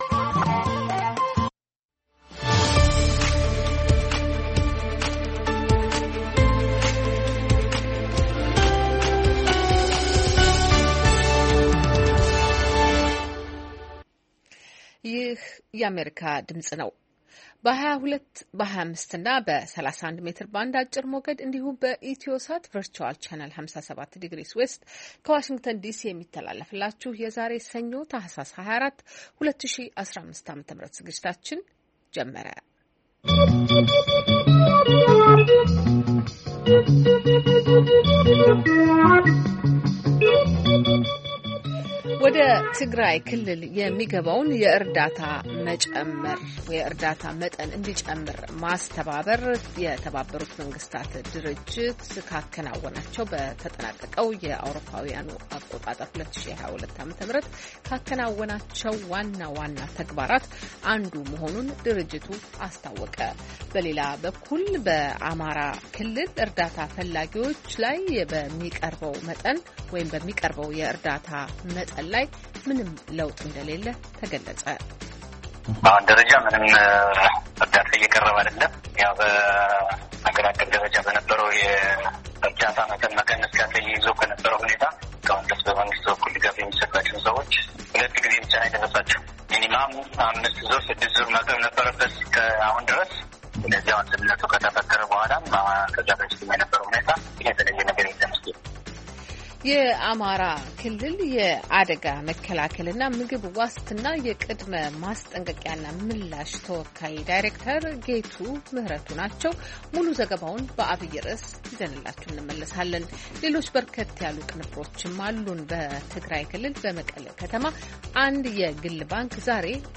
ሰኞ፡-ከምሽቱ ሦስት ሰዓት የአማርኛ ዜና